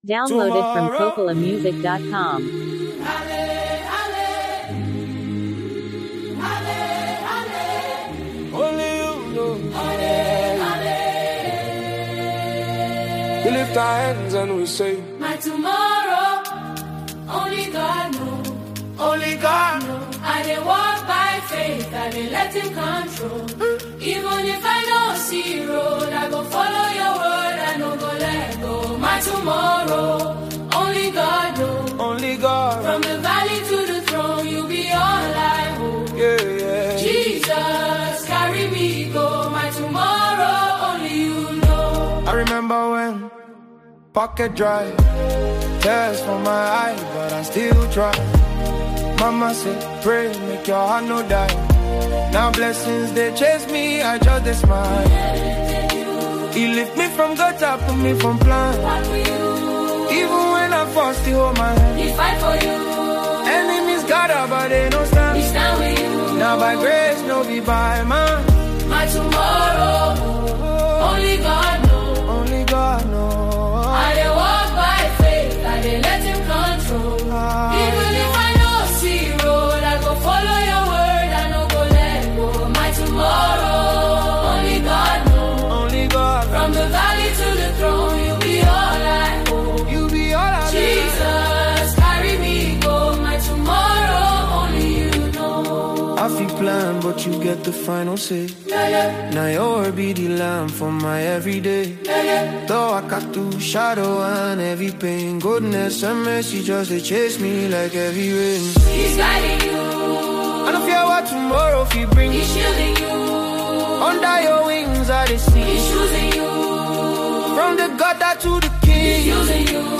a powerful and uplifting song
Through its inspiring lyrics and soulful harmonies